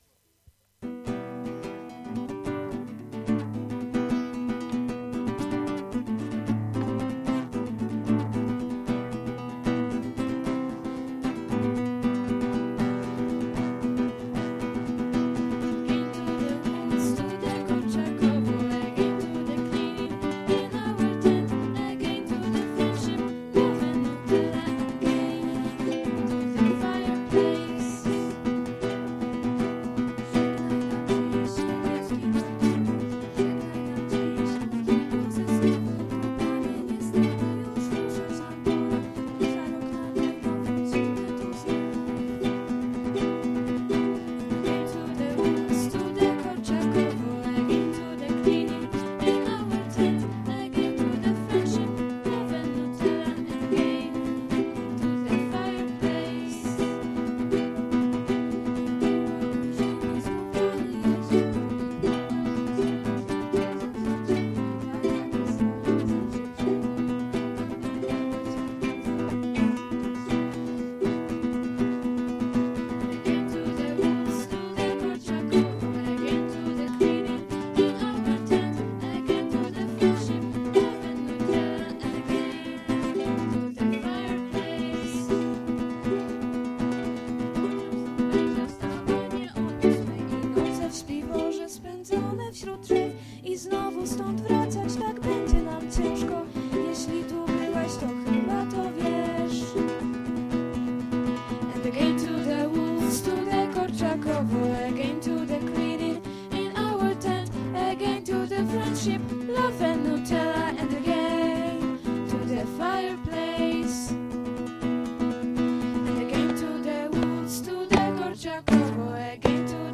Najnowsze wydawnictwo KFD (Korczakowskiej Fabryki Dźwięków) – nagrania z Festiwalu Piosenki Naszej 2016 z pierwszego turnusu.